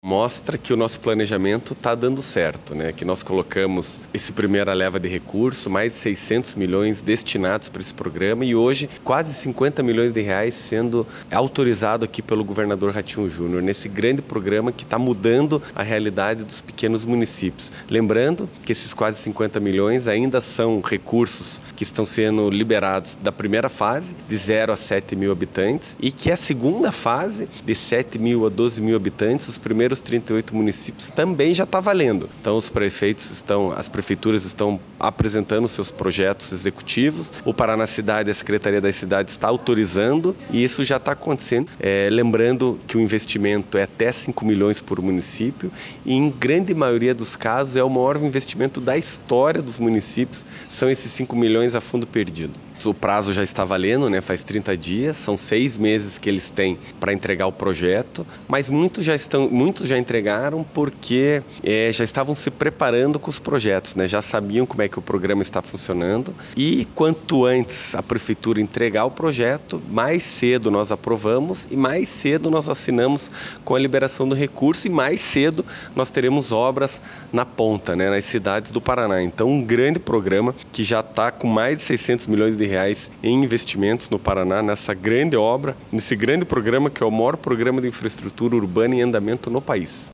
Sonora do secretário das Cidades, Eduardo Pimentel, sobre a liberação de mais R$ 43,6 milhões para 11 municípios pelo Asfalto Novo, Vida Nova